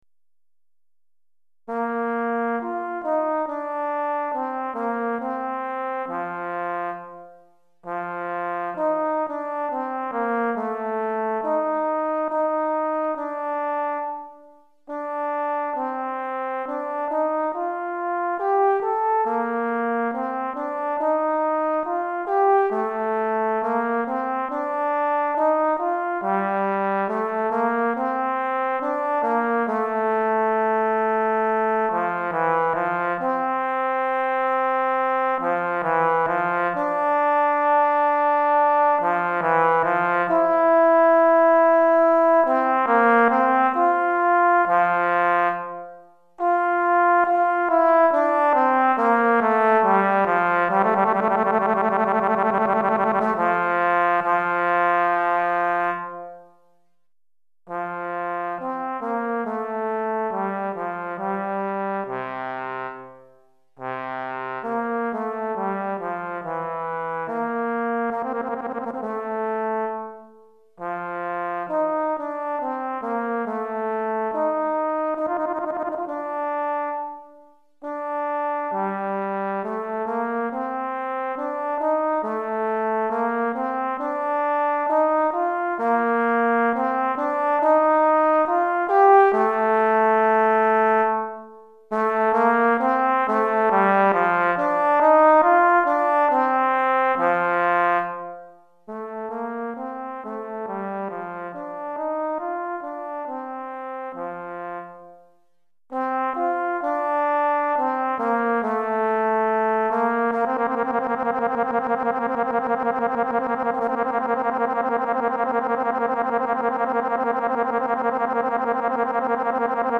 Trombone Solo